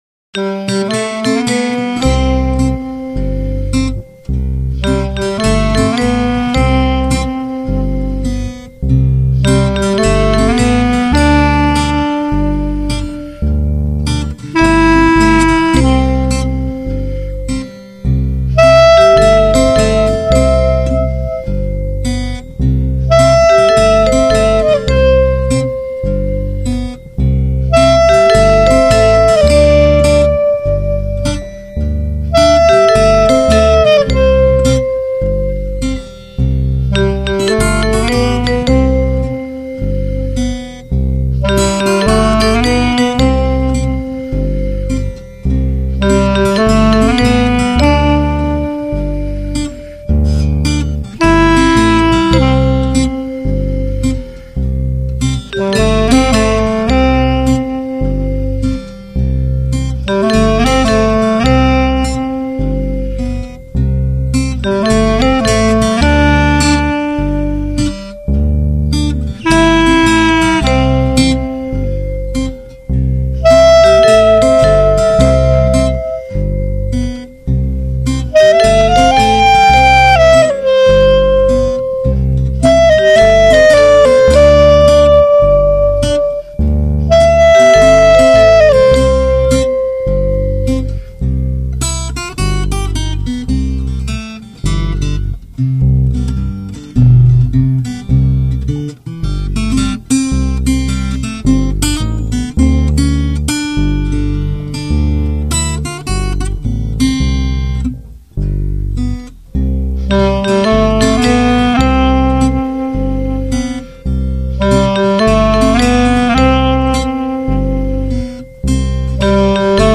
Guitar, Bass, Clarinet and Vibes